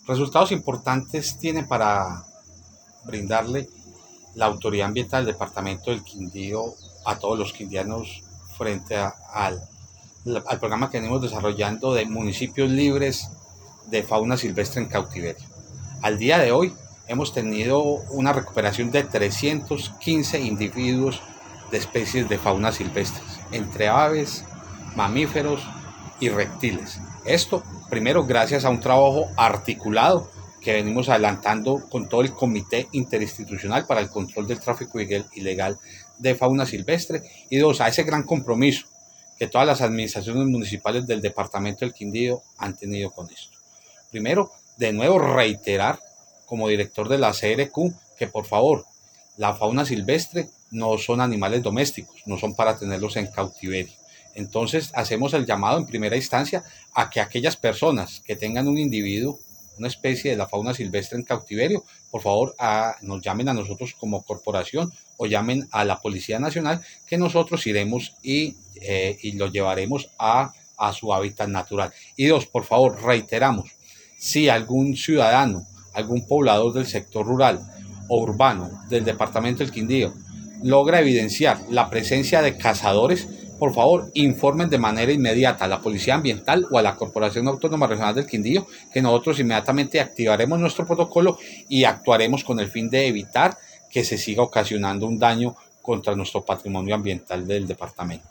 AUDIO JOSÉ MANUEL CORTÉS OROZCO-DIRECTOR GENERAL DE LA CRQ: